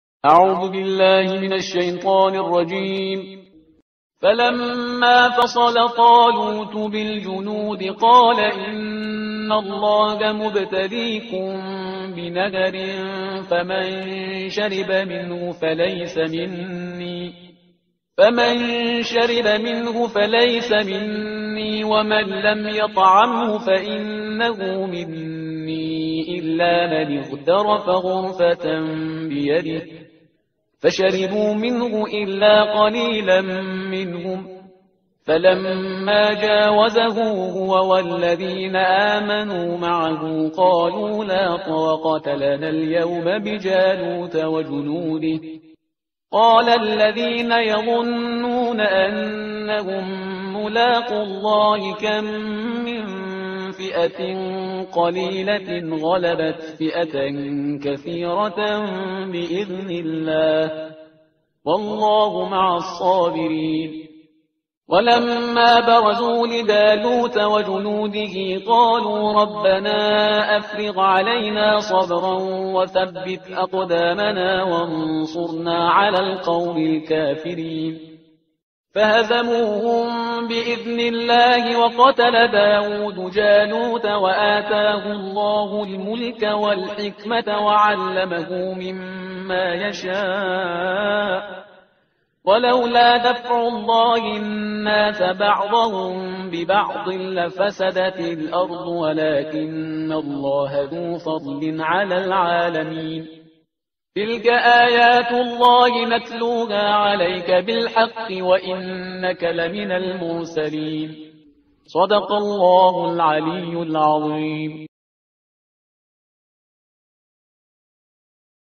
ترتیل صفحه 41 قرآن با صدای شهریار پرهیزگار